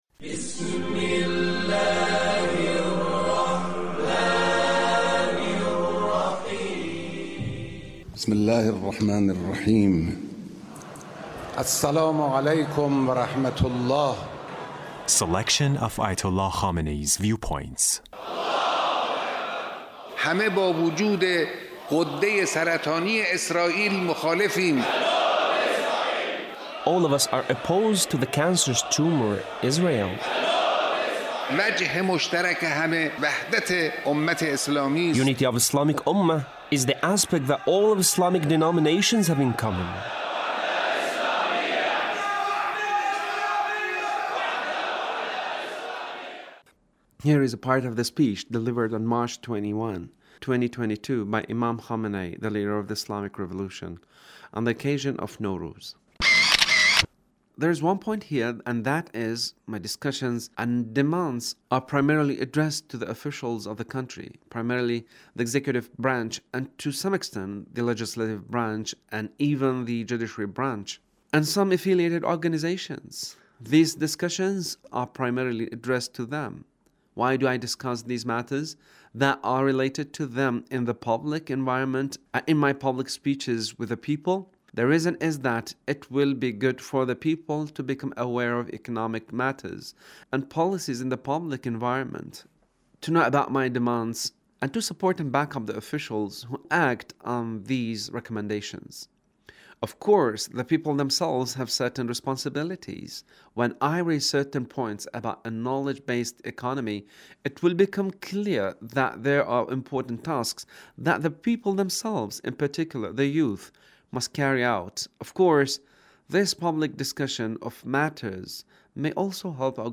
Leader's Speech on Knowledge Based Economy